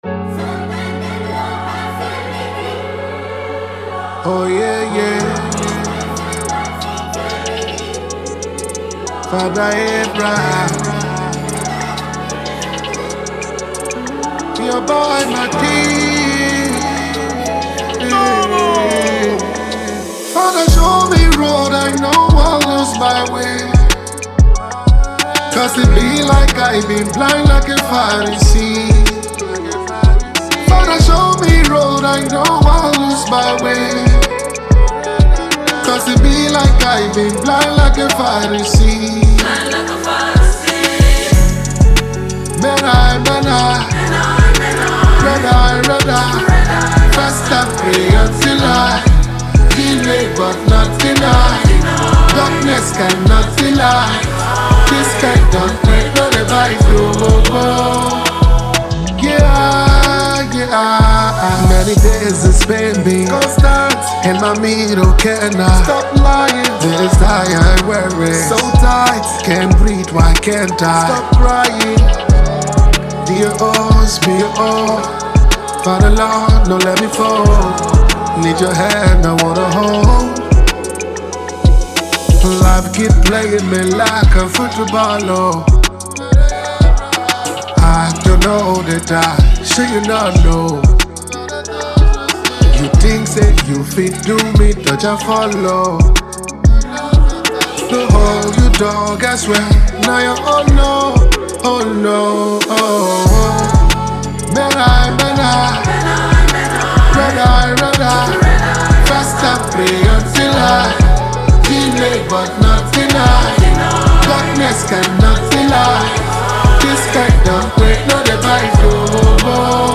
a refreshing feel
Afro Fusion